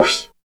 100 MD CYM-L.wav